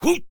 文件 文件历史 文件用途 全域文件用途 Enjo_atk_02_2.ogg （Ogg Vorbis声音文件，长度0.3秒，194 kbps，文件大小：7 KB） 源地址:地下城与勇士游戏语音 文件历史 点击某个日期/时间查看对应时刻的文件。